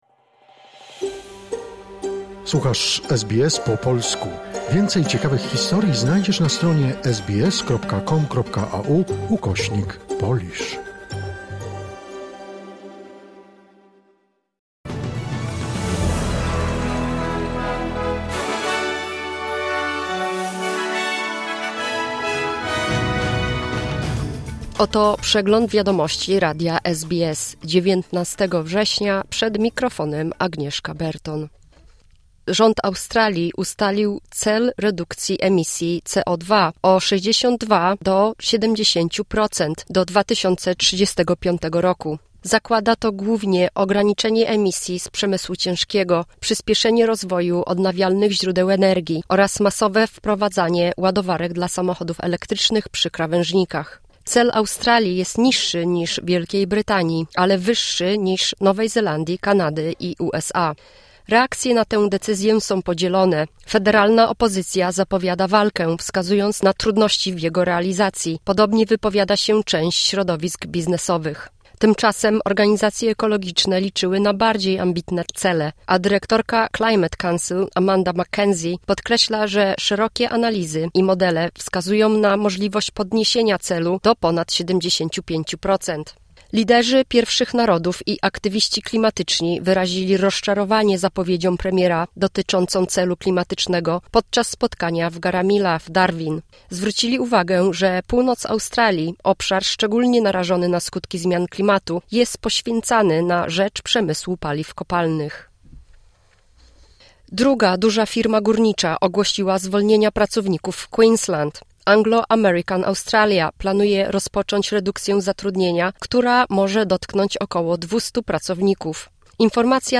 Wiadomości 19 września SBS News Flash